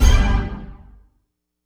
orch hit.wav